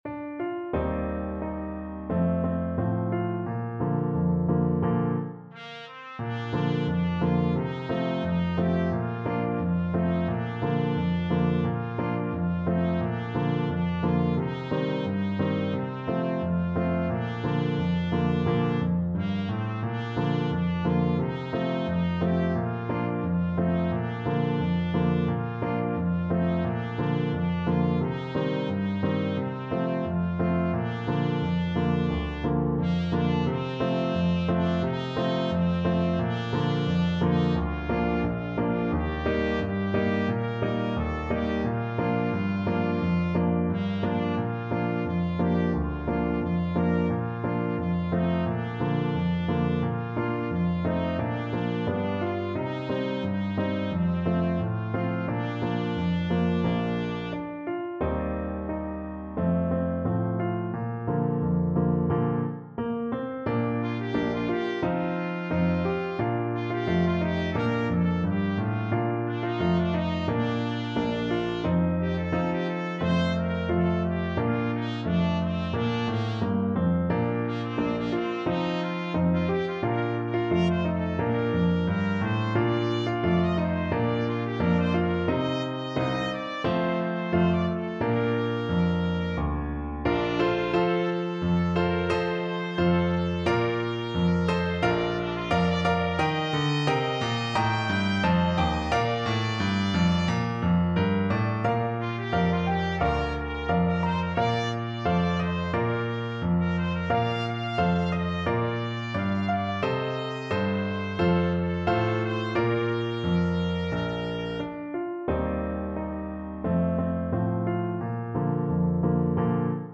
Trumpet version
Andante =c.88
4/4 (View more 4/4 Music)
Traditional (View more Traditional Trumpet Music)